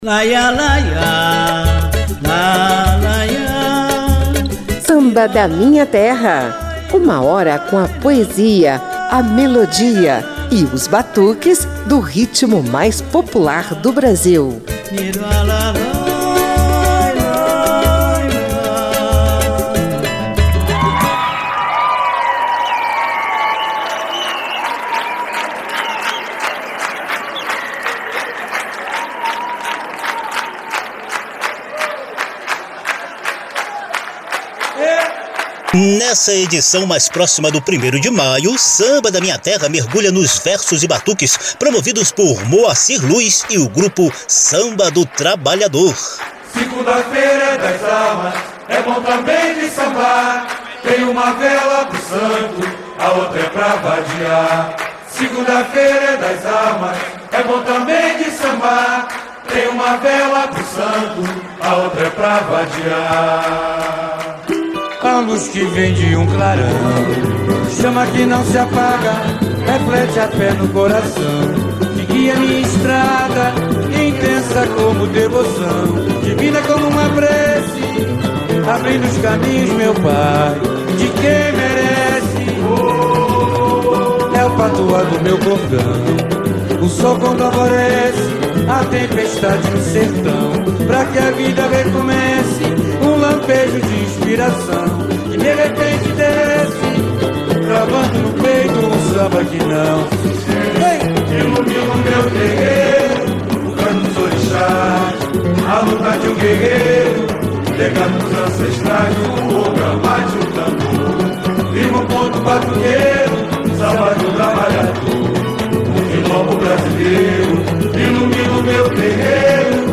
Samba da Minha Terra celebra o 1° de maio por meio dos versos e batuques do Samba do Trabalhador. O grupo foi criado pelo sambista Moacyr Luz em 2005 para comandar a tradicional roda de samba das segundas-feiras no Clube Renascença, um verdadeiro “quilombo” de resistência da cultura negra, localizado no bairro do Andaraí, zona norte do Rio de Janeiro.